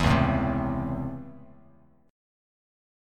C#mM7#5 chord